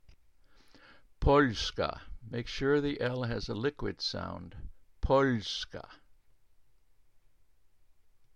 Polish Words -- Baritone Voice
Polska (POHLy - skah)
The Polish /O/ is a pure vowel and is pronounced like the [O] in "off".